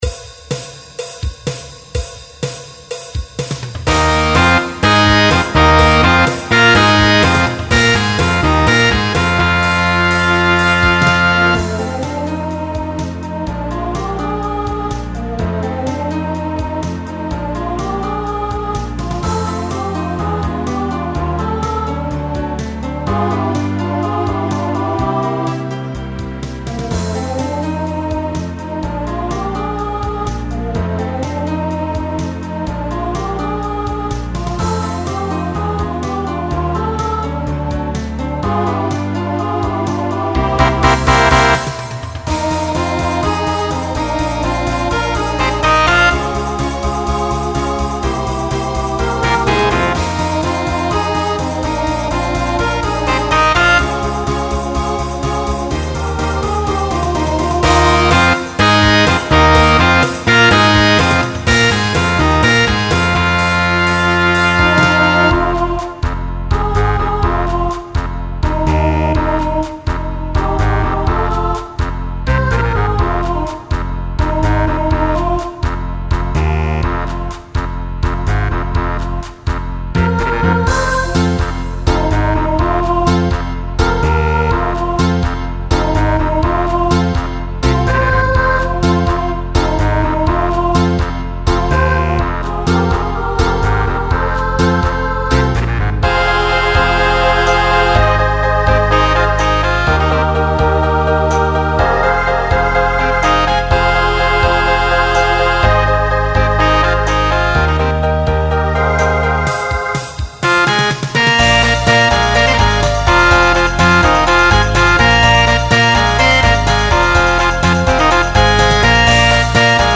New SATB voicing for 2022.
Genre Broadway/Film